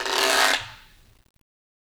Guiro-M_v1_Sum.wav